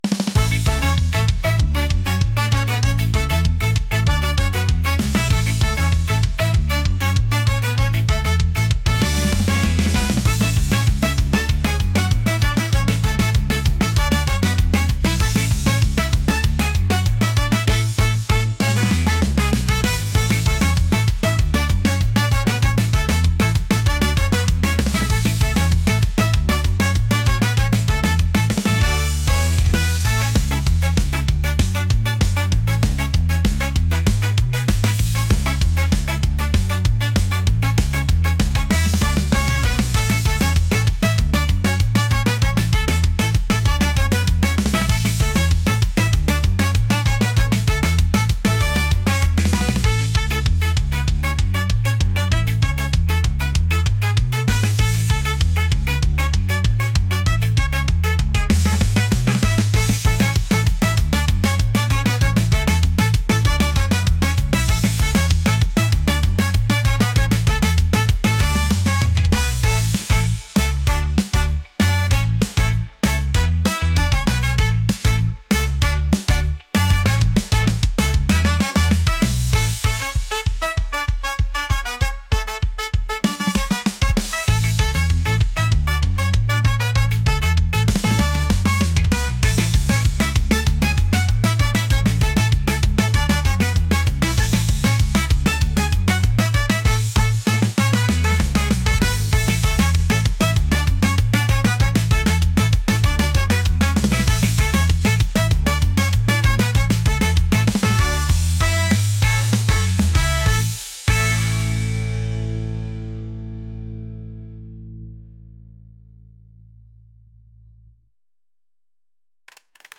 ska | upbeat | energetic